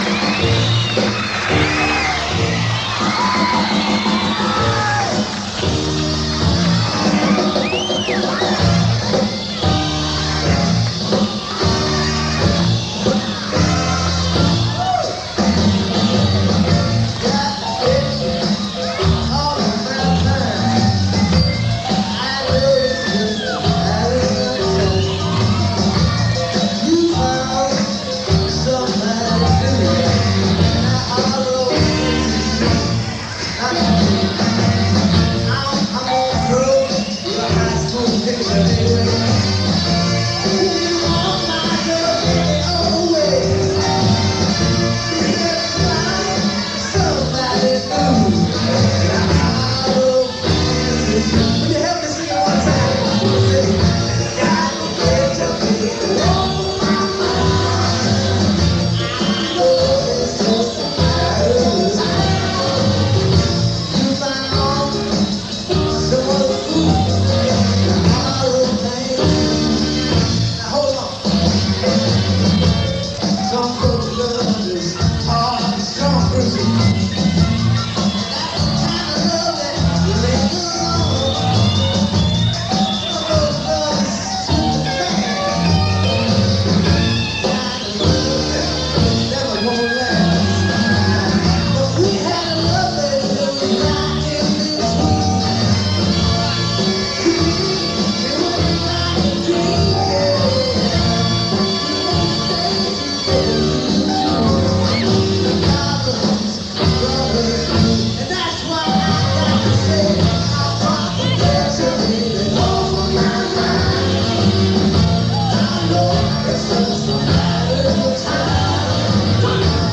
guitar and backing vocals
First performed: May 12, 1977 (Red Bank, NJ)